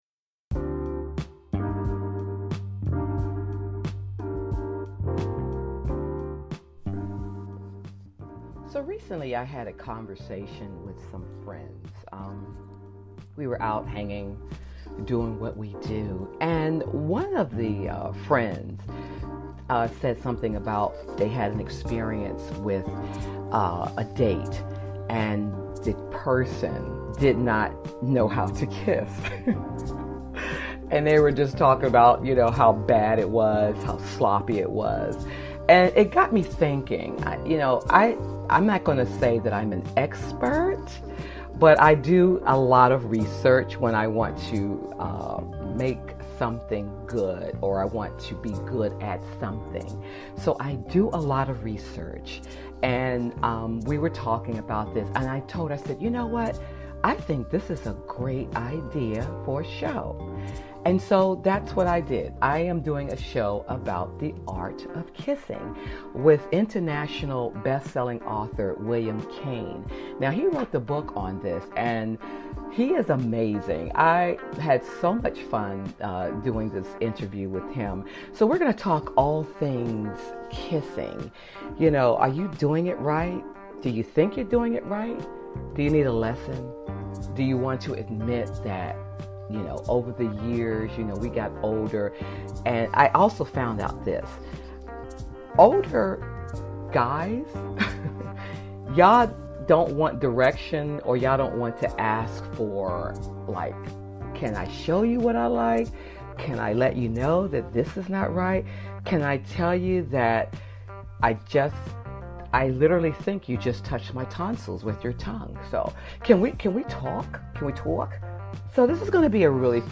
Talk Show
Podcast Bio : is a lively, conversational podcast that flips the script on aging.